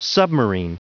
Prononciation du mot submarine en anglais (fichier audio)
Prononciation du mot : submarine